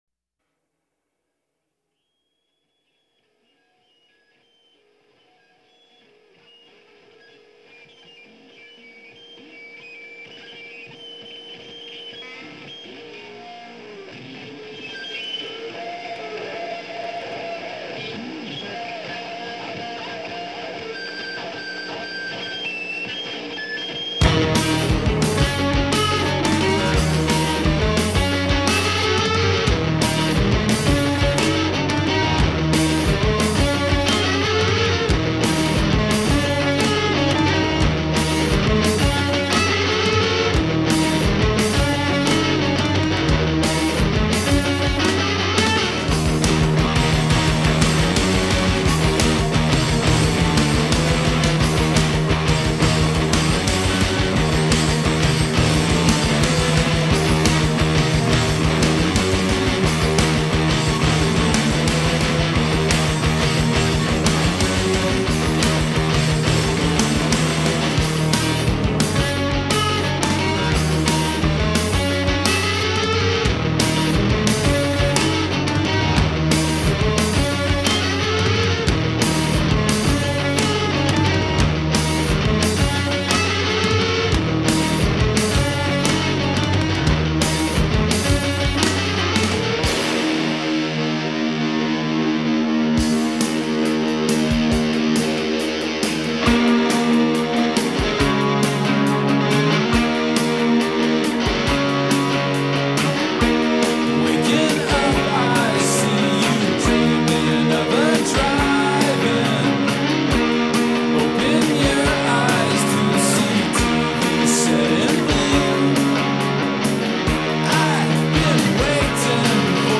Жанр: Alternative Rock